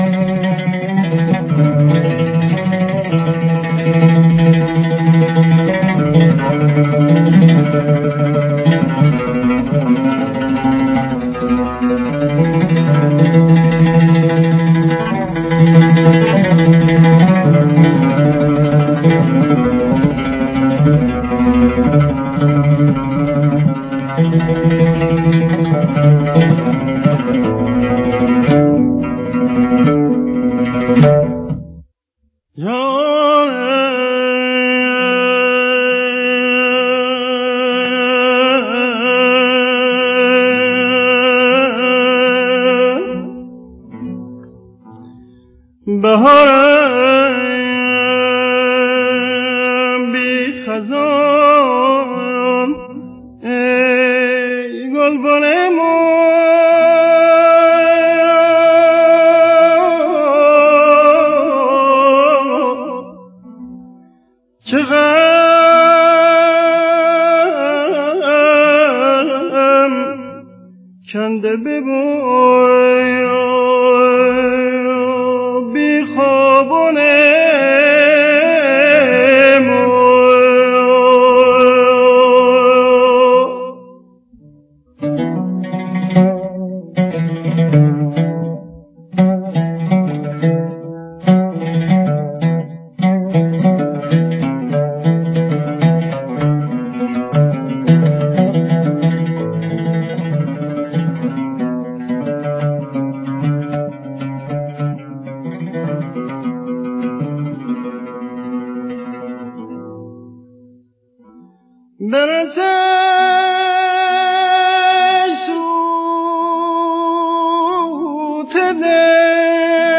نمونه برجسته ی آواز لری در